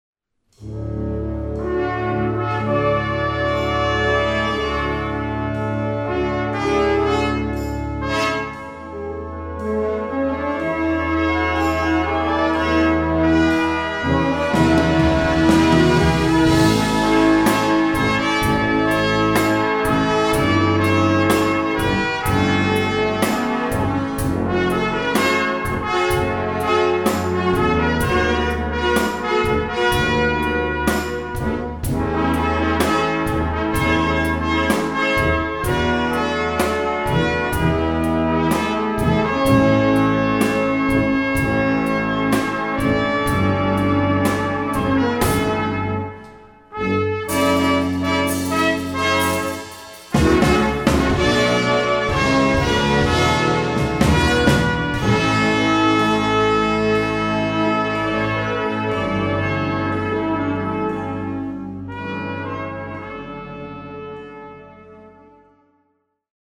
Instrumentation: Blasorchester
Sparte: Popularmusik